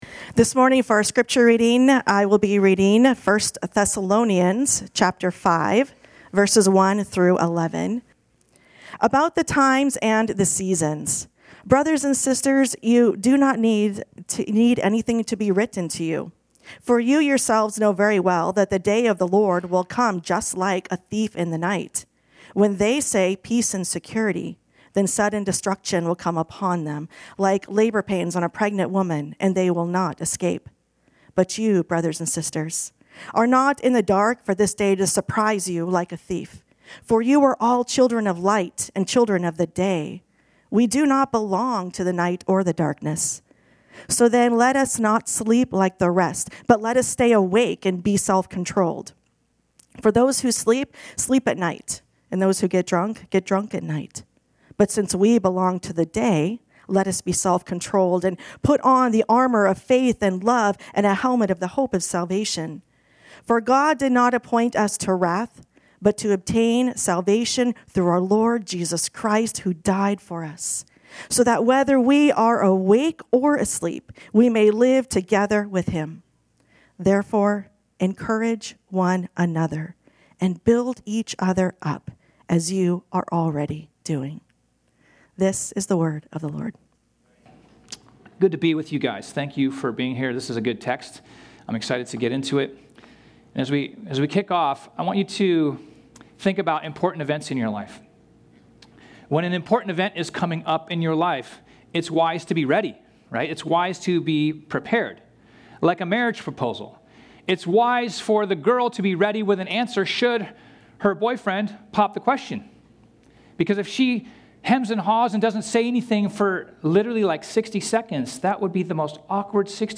This sermon was originally preached on Sunday, August 10, 2025.